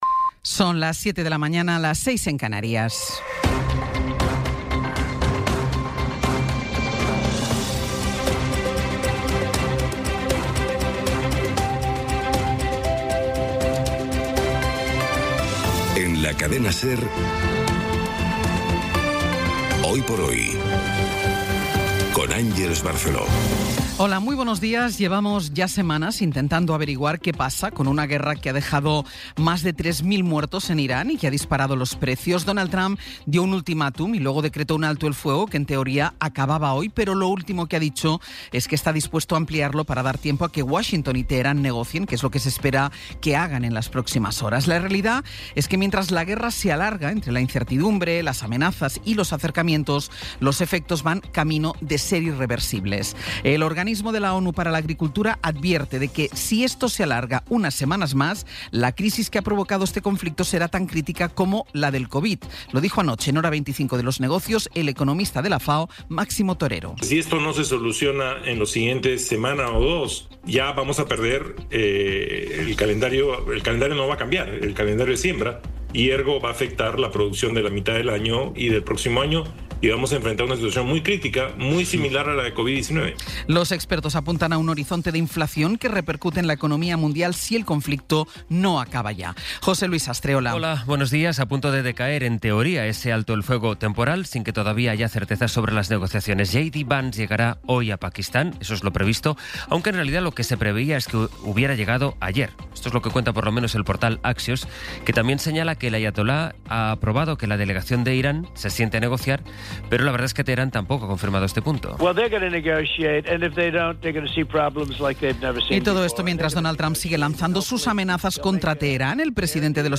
Resumen informativo con las noticias más destacadas del 21 de abril de 2026 a las siete de la mañana.